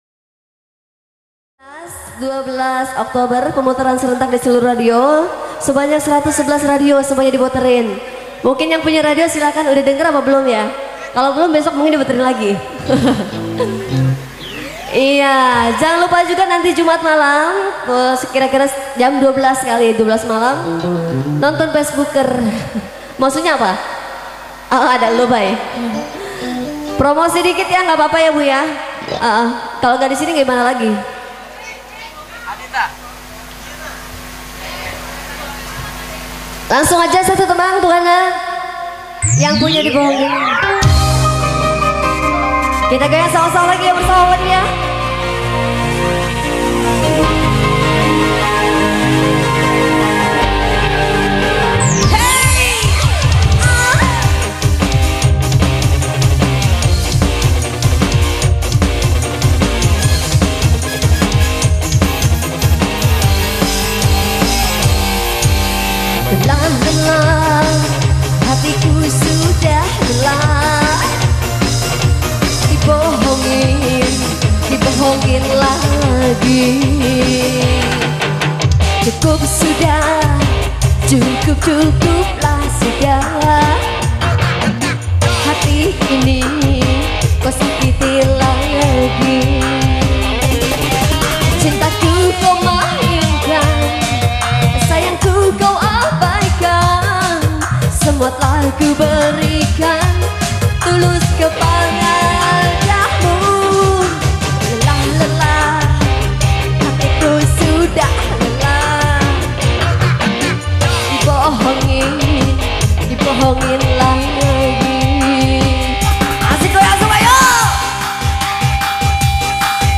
Lagu Dangdut koplo